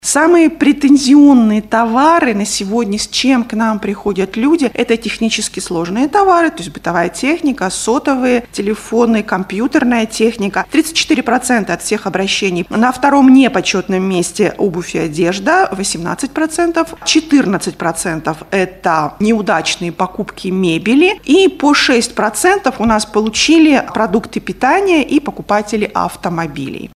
на пресс-конференции «ТАСС-Урал».